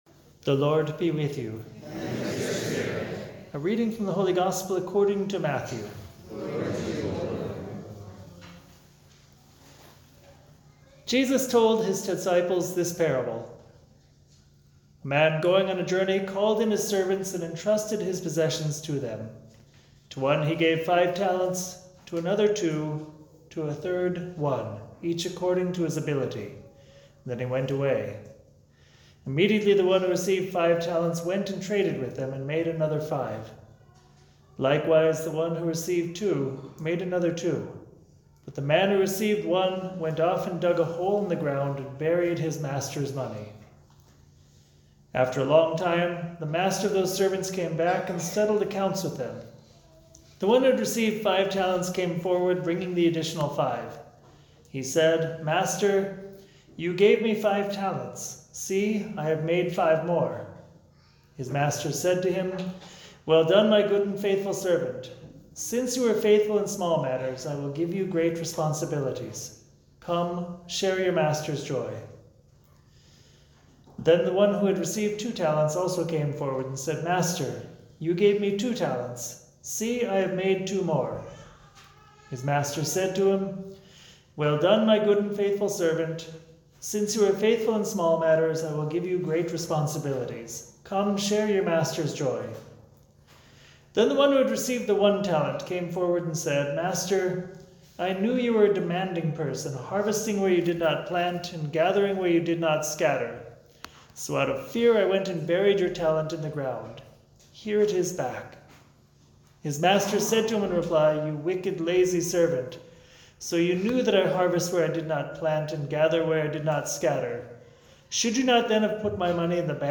Homily
for the 33rd Sunday in Ordinary Time celebrated during Regnum Christi Day at the Shrine of St. Joseph, Stirling, NJ.